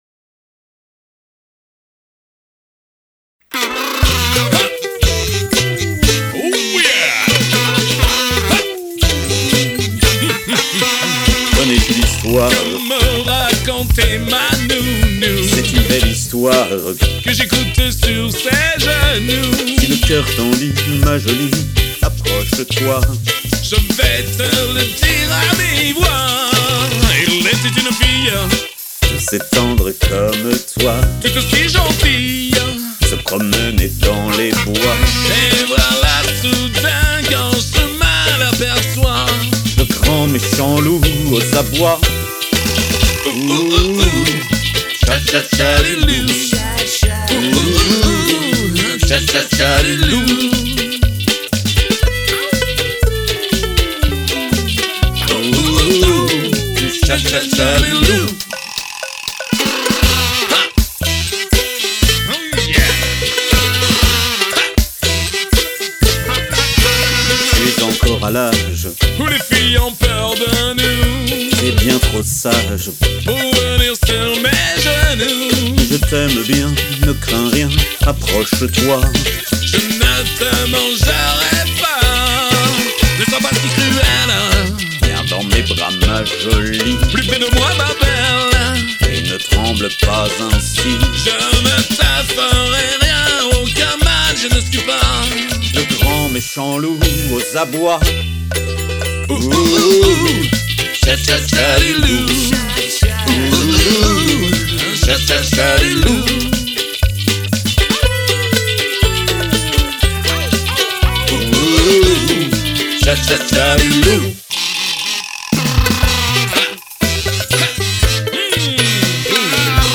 Moderato
duo